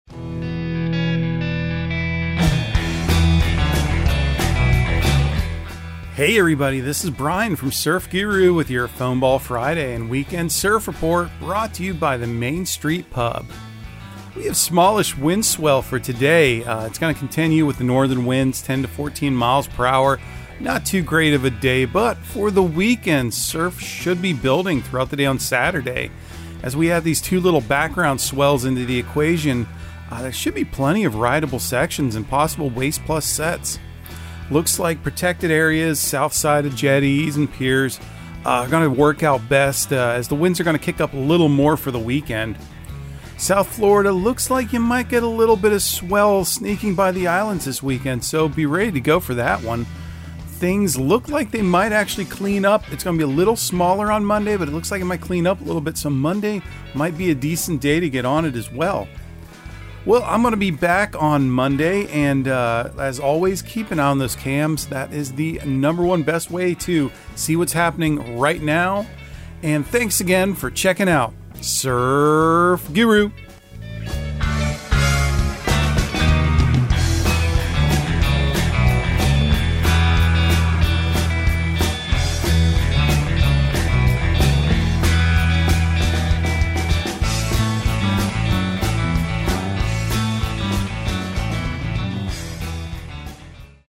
Surf Guru Surf Report and Forecast 10/21/2022 Audio surf report and surf forecast on October 21 for Central Florida and the Southeast.